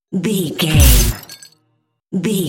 Dramatic hit hiss electricity debris
Sound Effects
Atonal
heavy
intense
dark
aggressive
hits